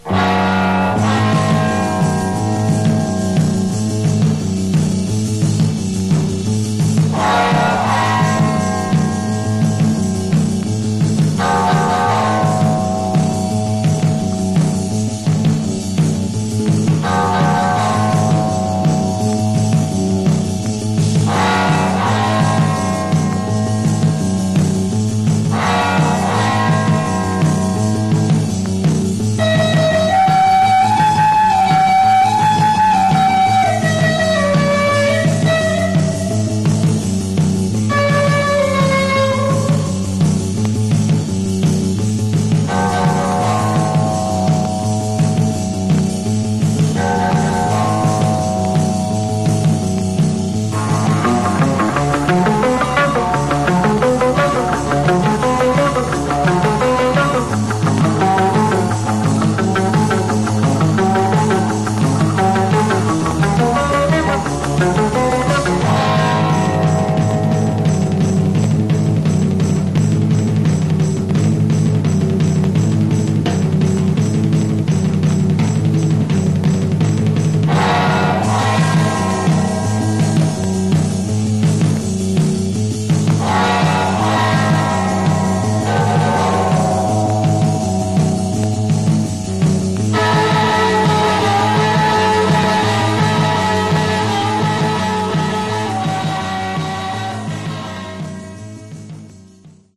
Genre: Surf/Cars